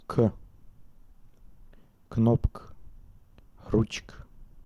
к, кнопк, ручк cup